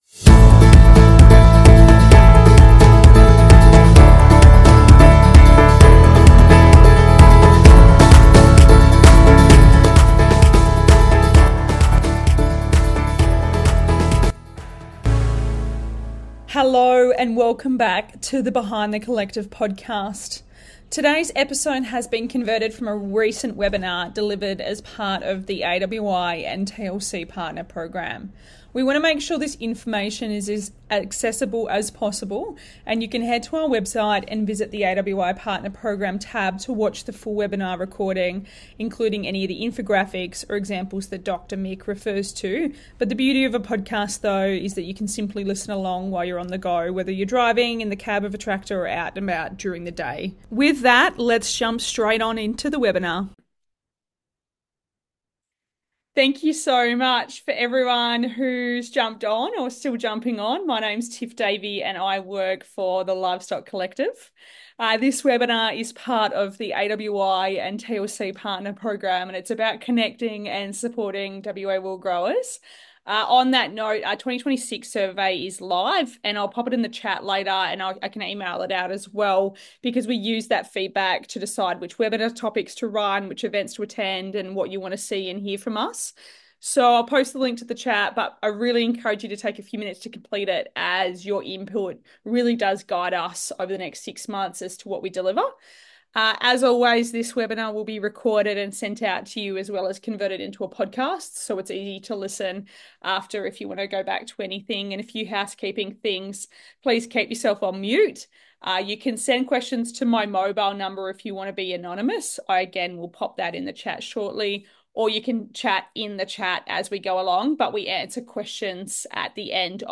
This is a podcast episode converted over from a recent webinar, Sheep Reproduction: Seasonality, Ewe Lambs, and Maximising Lambing
The webinar also touched on ewe lambs, outlining the key yes and no’s when it comes to breeding them. Concluding with a Q&A session, answering submitted questions as well as any additional questions.